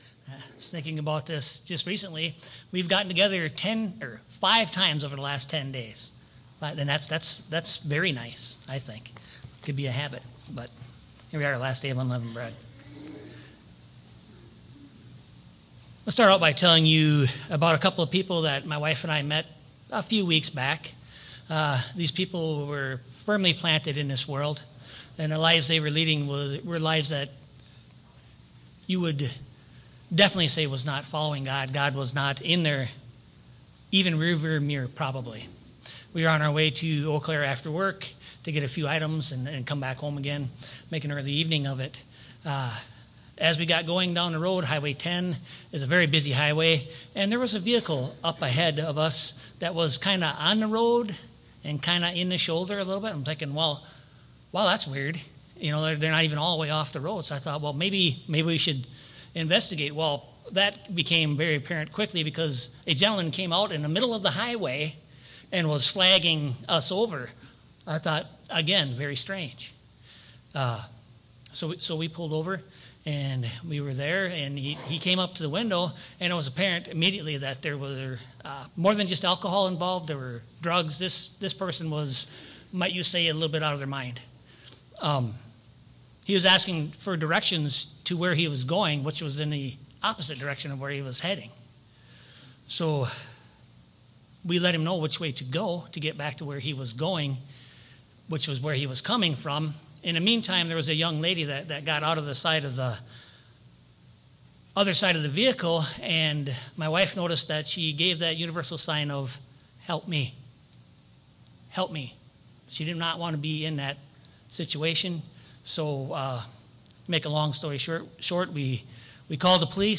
Given in Eau Claire, WI